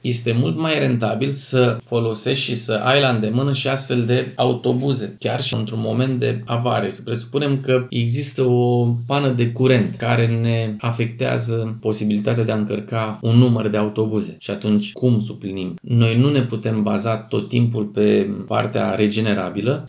Primarul Ștefan Ilie spune că autobuzele clasice sunt esențiale pentru flota de transport public, mai ales atunci când apar probleme neașteptate, cum ar fi penele de curent.